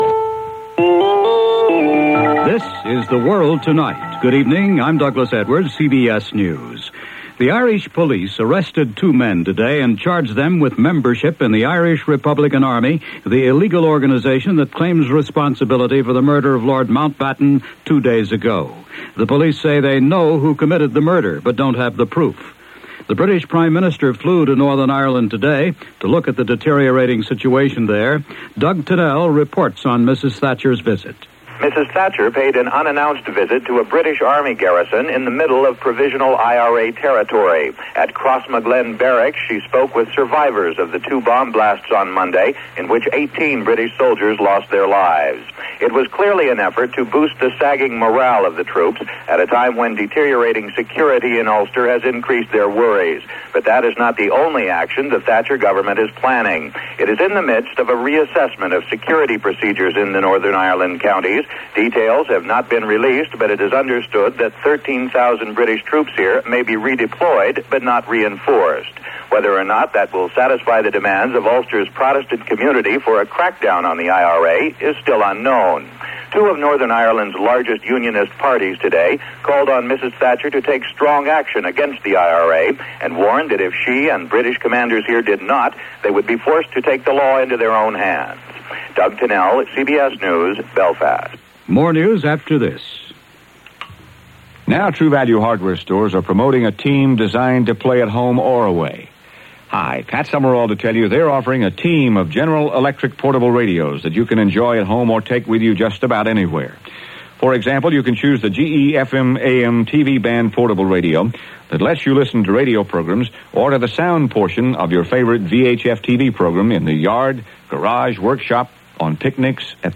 August 29, 1979 – CBS Radio – The World Tonight